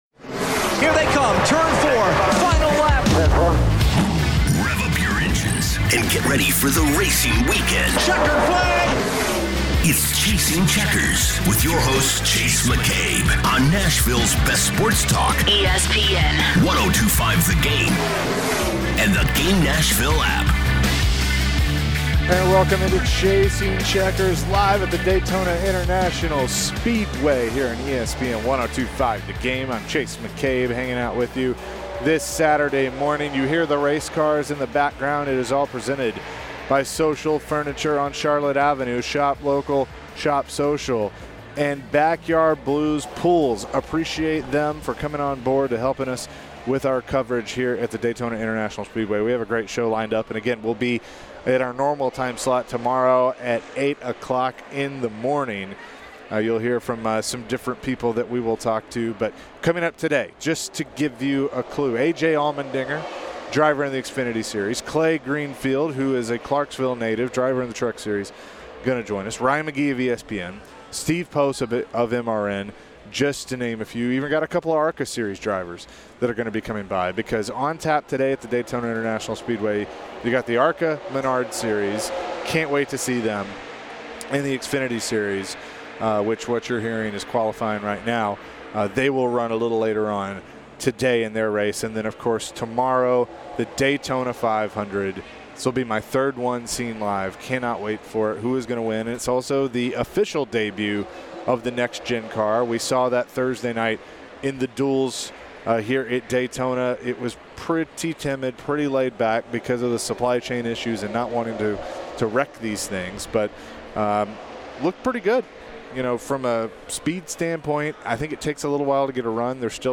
live in Daytona for the Daytona 500 weekend!